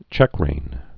(chĕkrān)